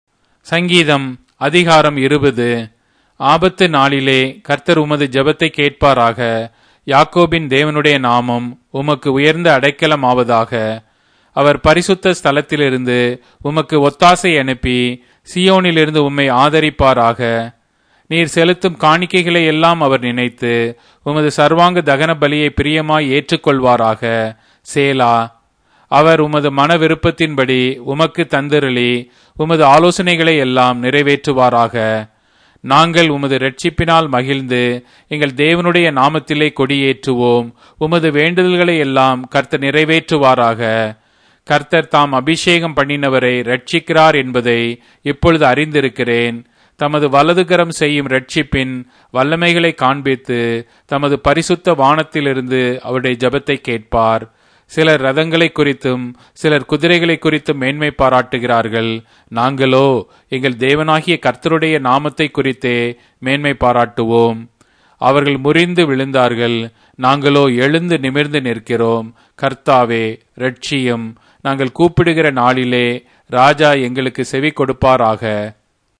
Tamil Audio Bible - Psalms 14 in Kjv bible version